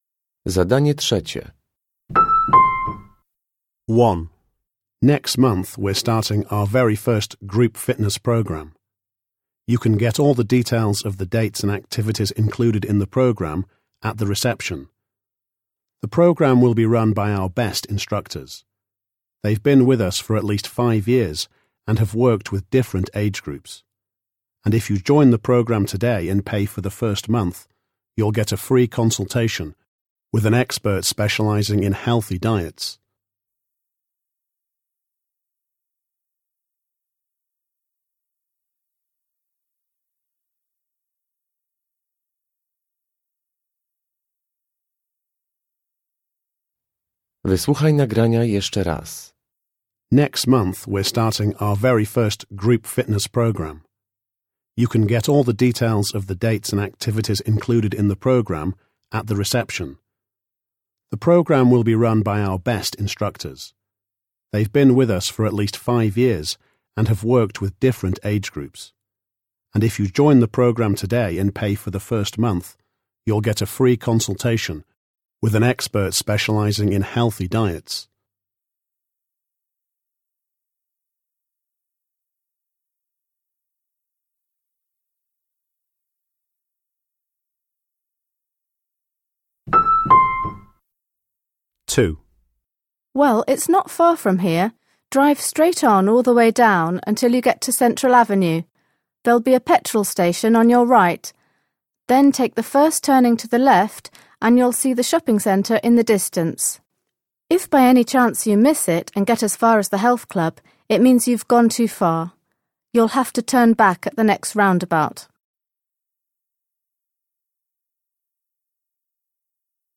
Uruchamiając odtwarzacz usłyszysz dwukrotnie cztery wypowiedzi związane z hałasem.
Uruchamiając odtwarzacz usłyszysz dwukrotnie sześć tekstów.